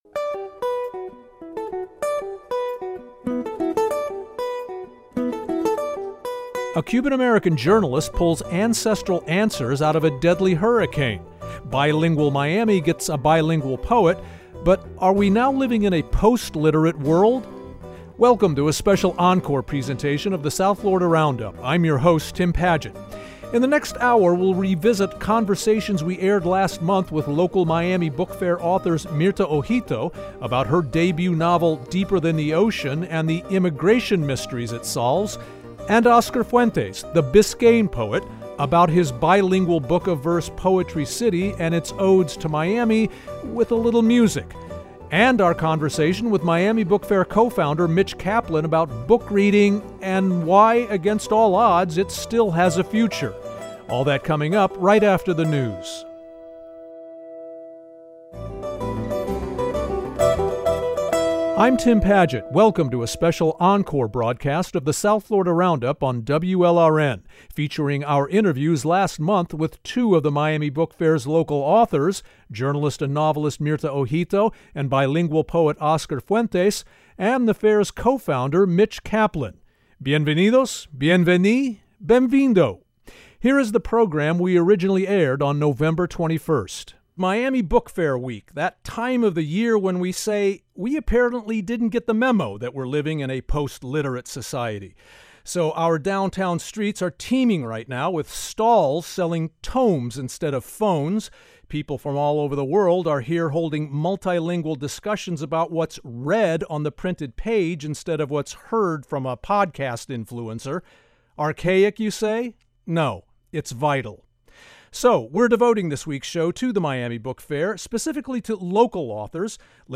1 Encore: An immigration mystery, bilingual odes to Miami and a post-literate world 50:56 Play Pause 14h ago 50:56 Play Pause Play later Play later Lists Like Liked 50:56 In a re-broadcast of The South Florida Roundup, we revisited conversations we aired last month with Miami Book Fair authors.